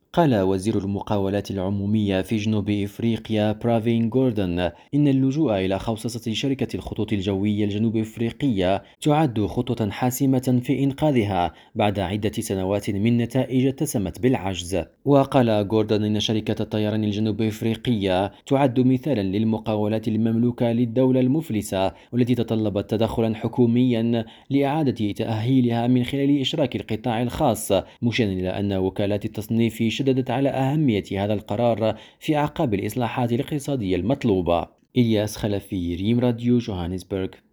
Bulletins d'information